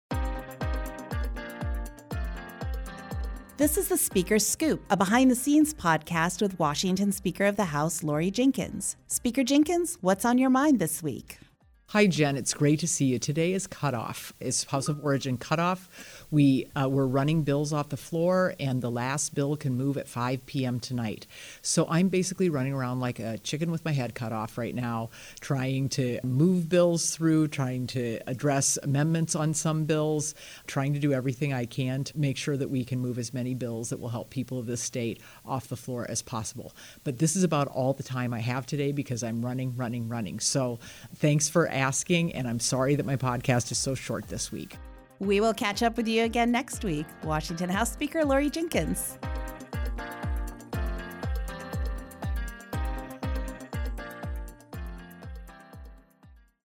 It’s House of Origin Cutoff, an important deadline in the session, so Speaker Jinkins only had 56 seconds to chat with us.